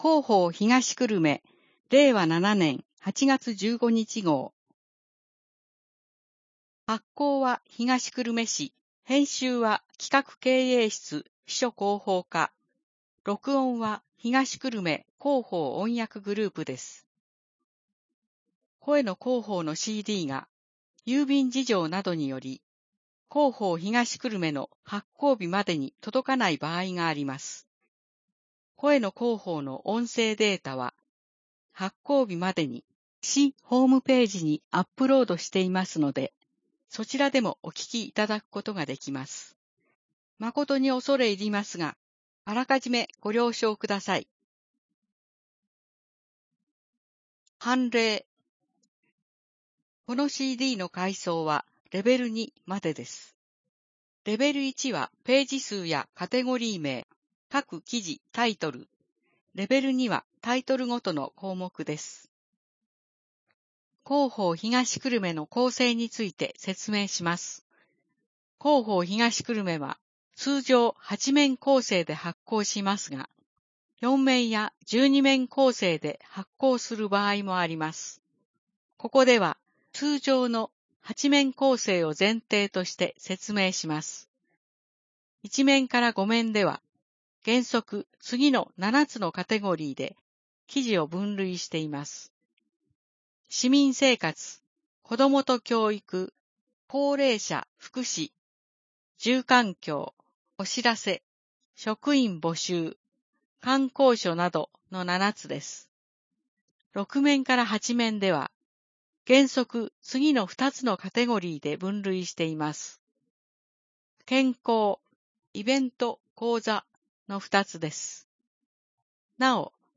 声の広報（令和7年8月15日号）